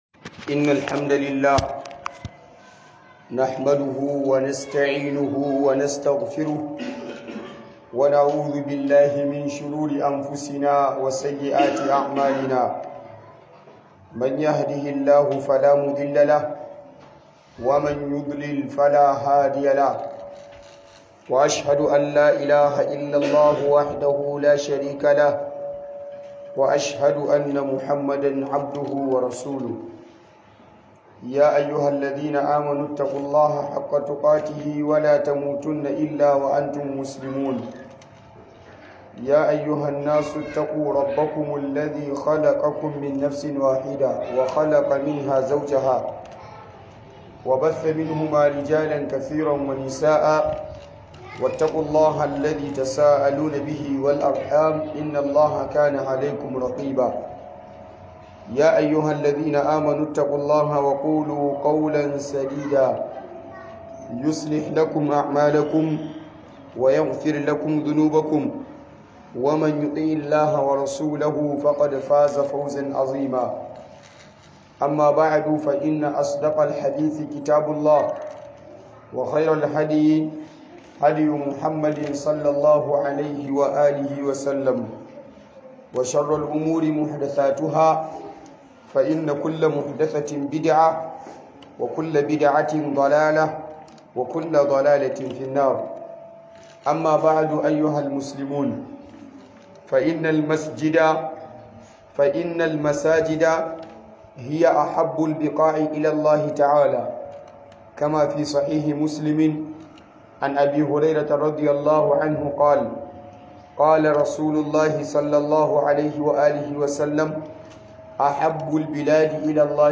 Huduba Gina Qabari A Masallaci